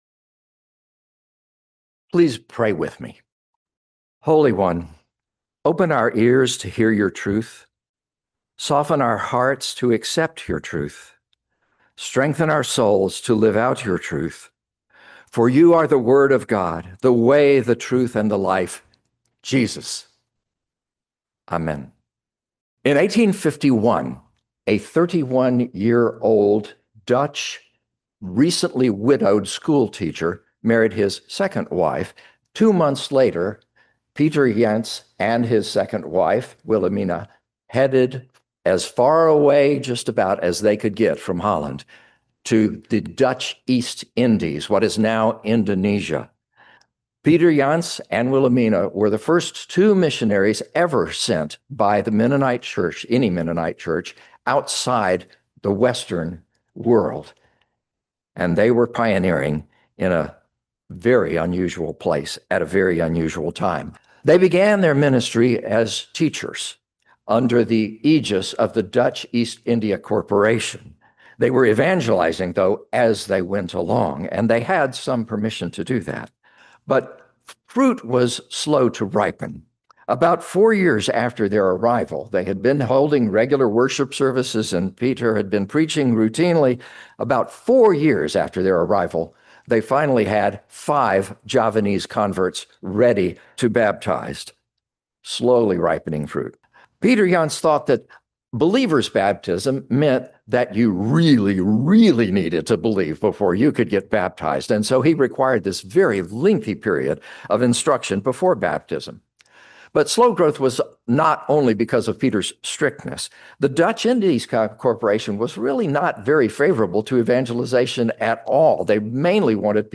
shared from the pulpit last week about the inspiring story of the formation of the Mennonite Church in Indonesia.